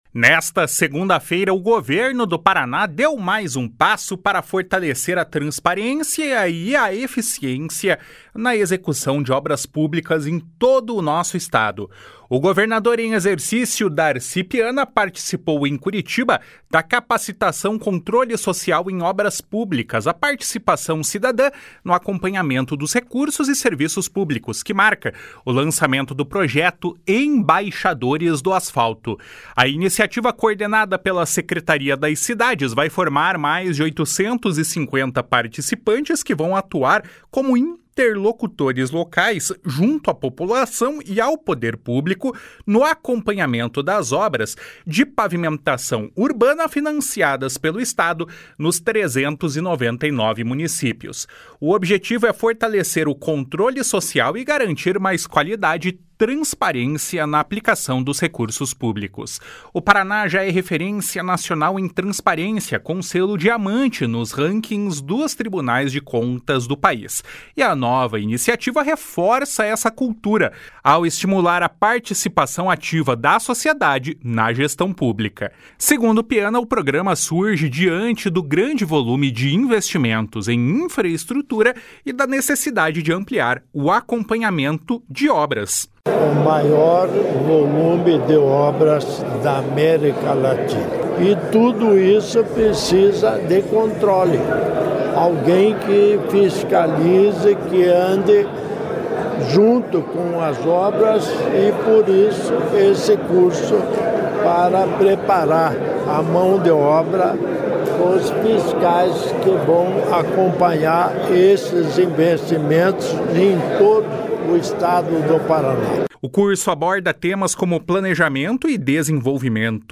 O secretário estadual das Cidades, Guto Silva, destacou que a capacitação ocorre em um momento de forte expansão dos investimentos em pavimentação urbana.
O prefeito de Mariópolis, Mário Eduardo Lopes Paulek, afirmou que a iniciativa ajuda a melhorar a execução dos projetos.